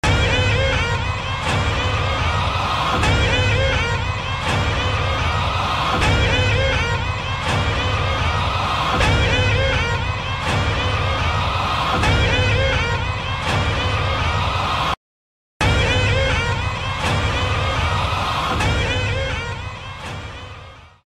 دانلود آهنگ پنی وایز از افکت صوتی انسان و موجودات زنده
جلوه های صوتی
دانلود صدای پنی وایز از ساعد نیوز با لینک مستقیم و کیفیت بالا